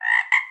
animalia_frog_2.ogg